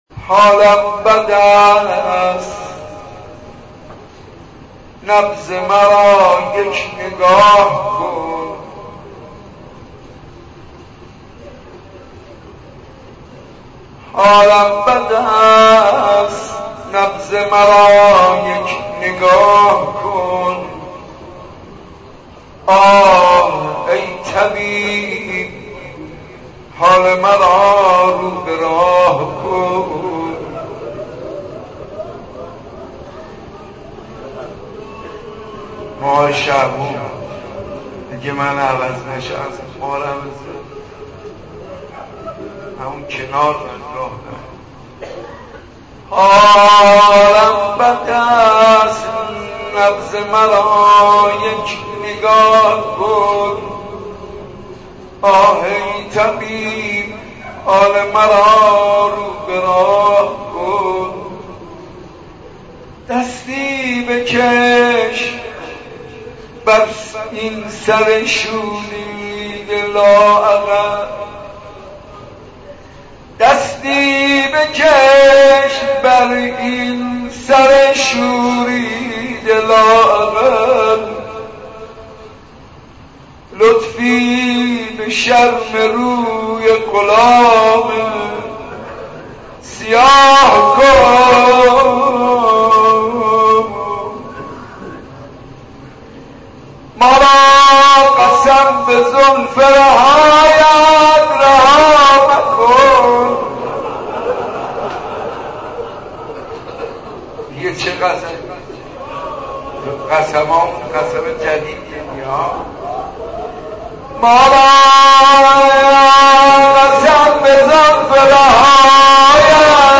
• مناجات با امام زمان عجل الله تعالی فرجه الشریف - حاج منصور ارضی